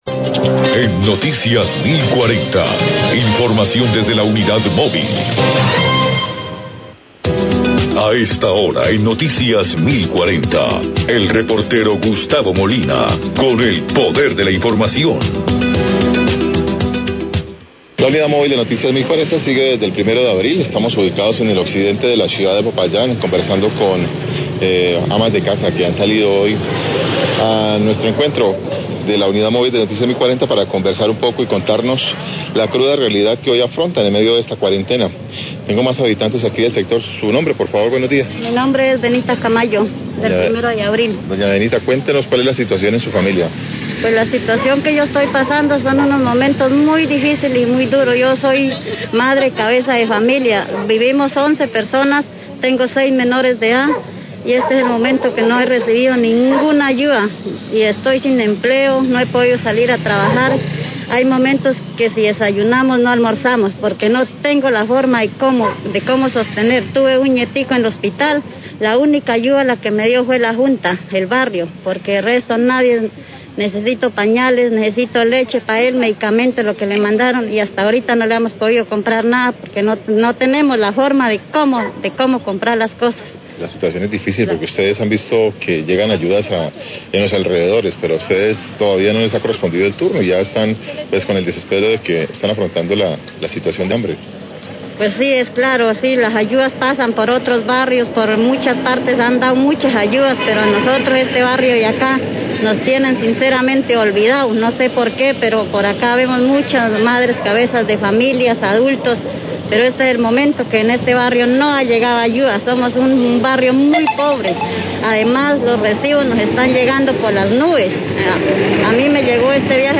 Radio
Desde el asentamiento 1 de Abril, amas de casa cuentan la cruda realidad que afrontan en medio de la cuarentena por coronavirus. No han recibido ayudas humanitarias, no han podido salir a trabajar y las facturas de energía están llegando muy caros.